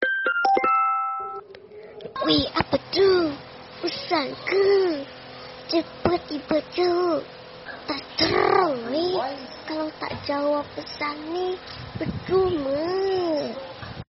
Genre: Nada notifikasi